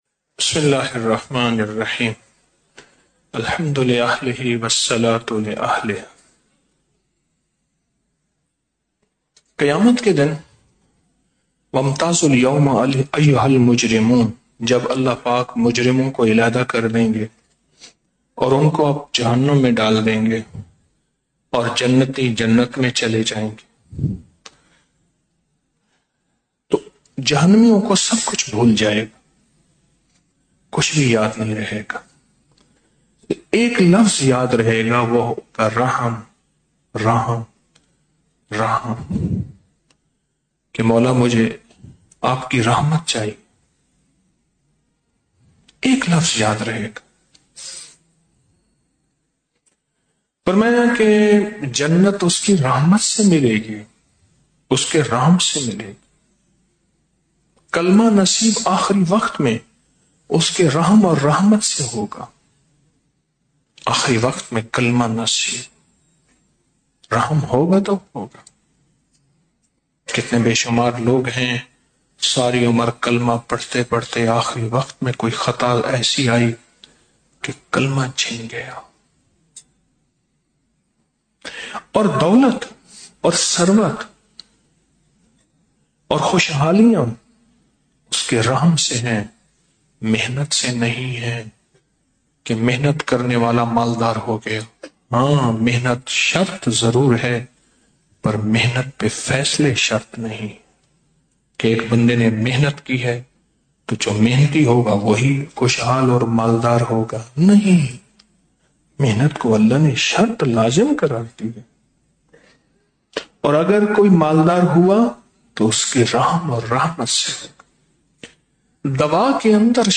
Audio Speech - 01 Ramadan After Salat Ul Taraweeh - 01 March 2025